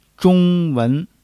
zhong1--wen2.mp3